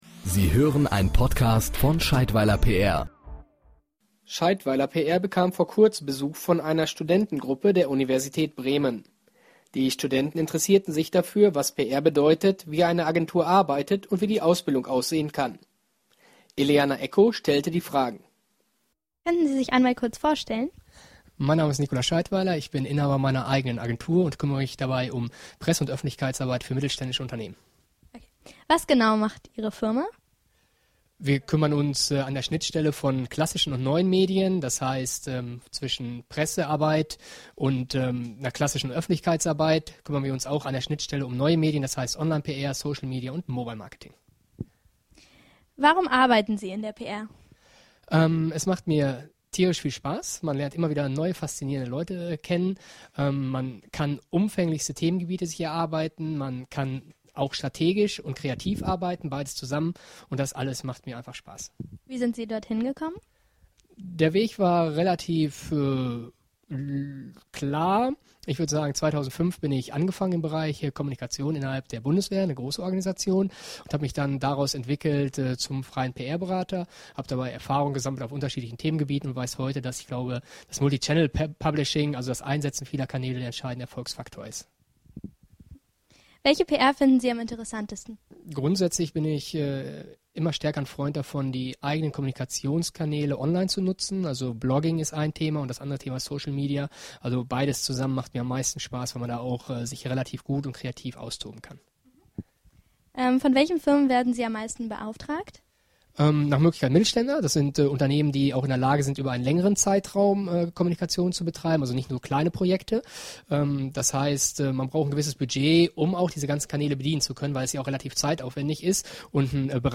PR-Podcast: Was macht eine PR-Agentur? – Interview